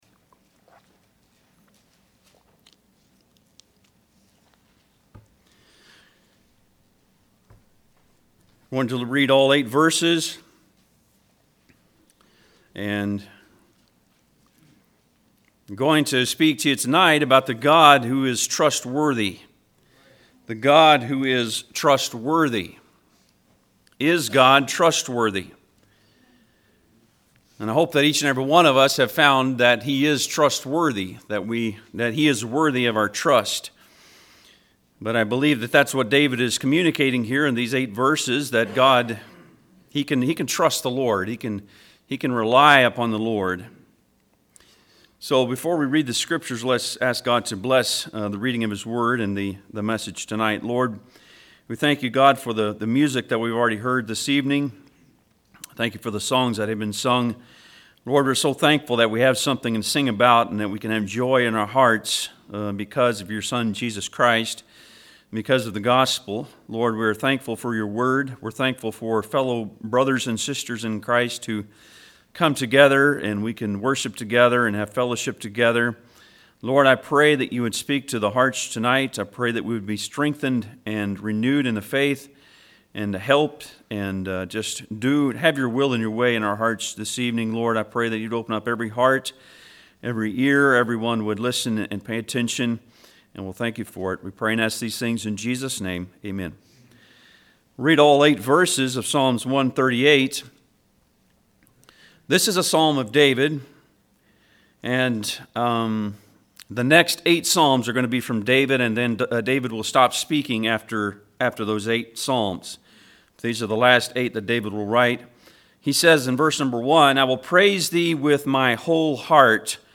Passage: Psalms 138:1-8 Service Type: Sunday pm